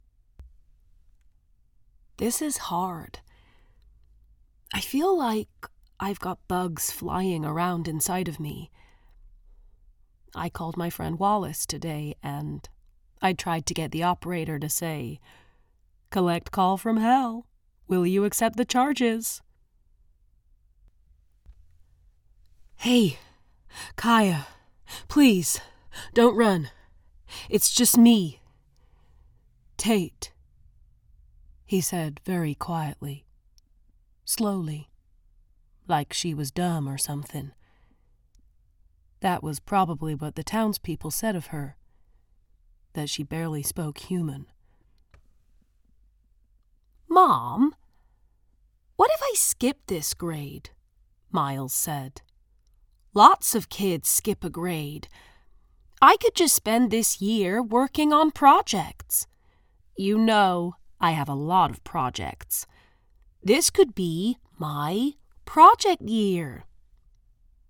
• Native Accent: Hereford, West Country
• Home Studio